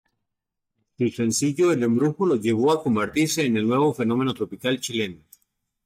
Read more Adj Noun Frequency 48k Hyphenated as chi‧le‧no Pronounced as (IPA) /t͡ʃiˈleno/ Etymology From Chile + -eno.